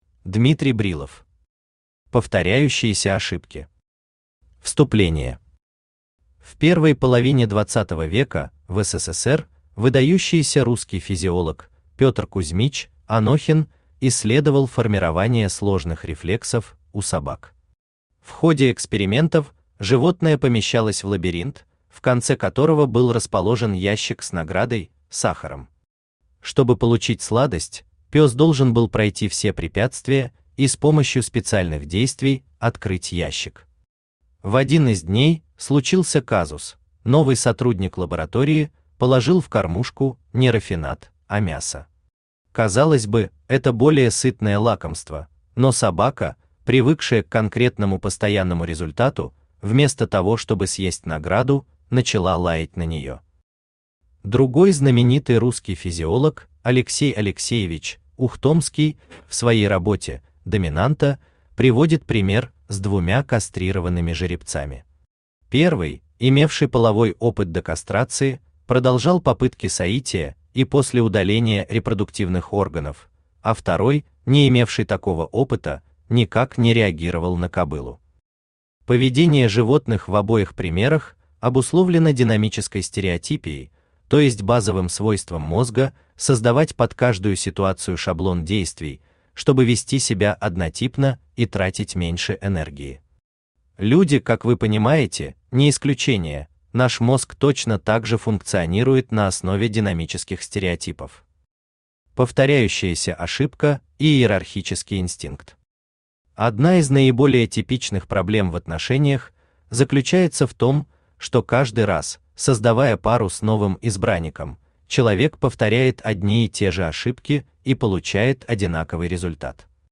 Aудиокнига Повторяющиеся ошибки в отношениях Автор Дмитрий Брилов Читает аудиокнигу Авточтец ЛитРес.